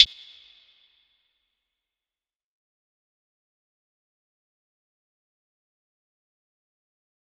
Closed Hats
DMV3_Hi Hat 3.wav